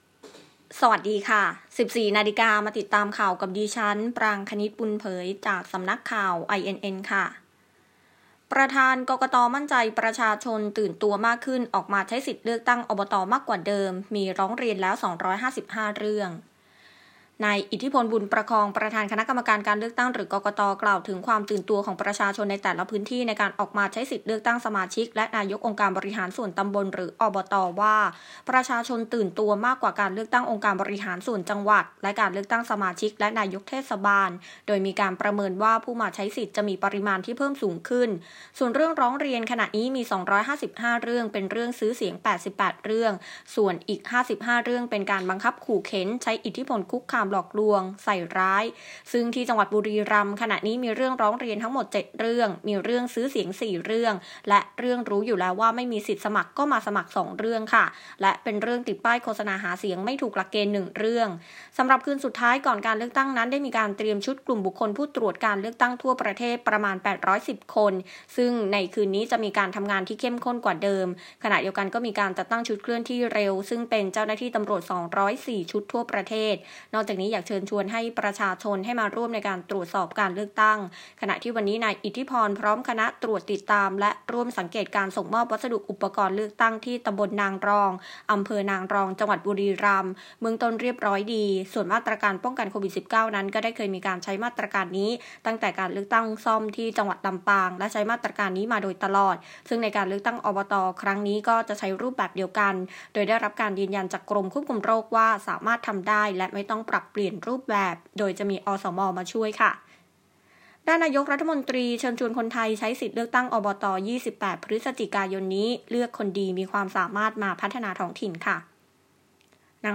คลิปข่าวต้นชั่วโมง ข่าว